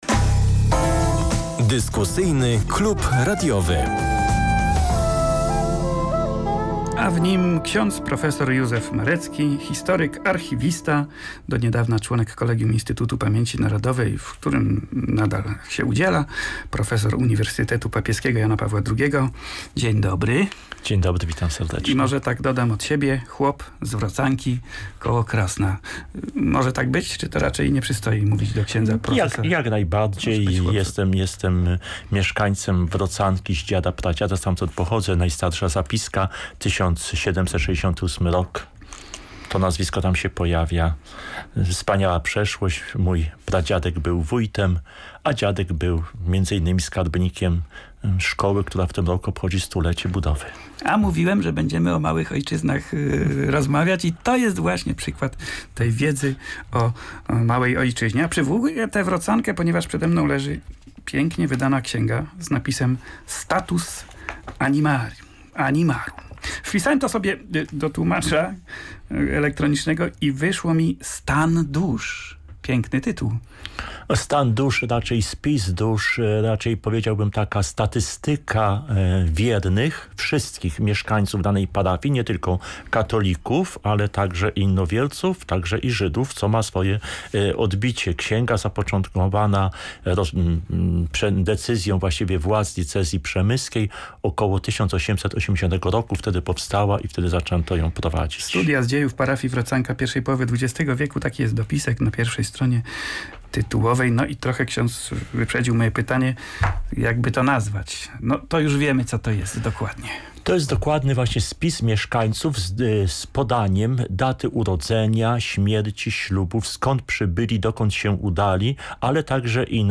W audycji dłuższa rozmowa o historii - zwłaszcza historii małych ojczyzn.